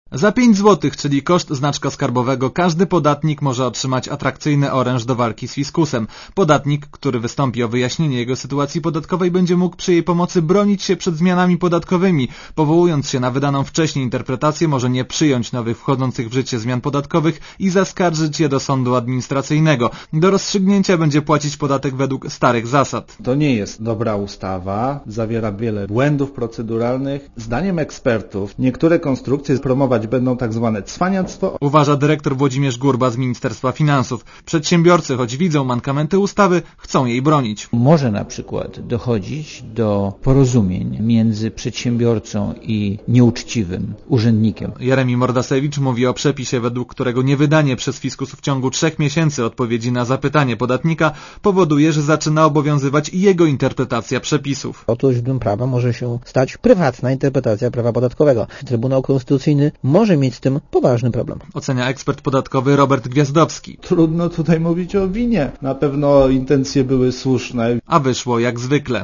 reportera Radia ZET*